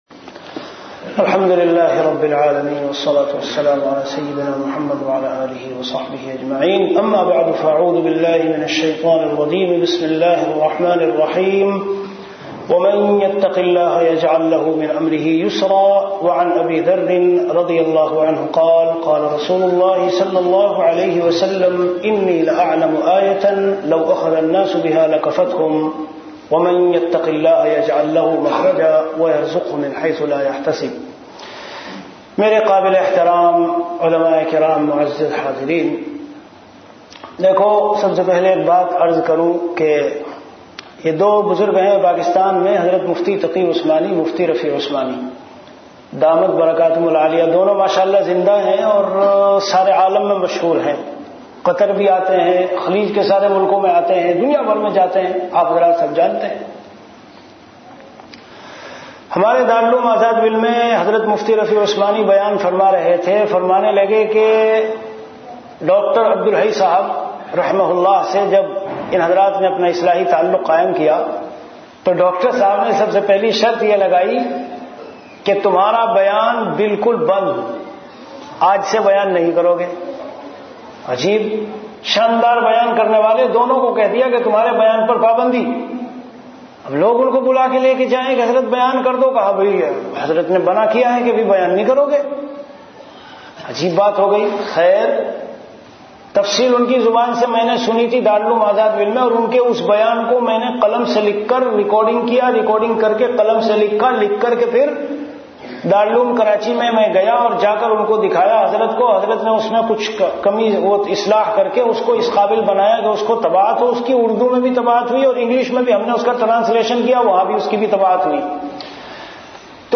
Delivered at Qatar.
Category Bayanat
Event / Time After Isha Prayer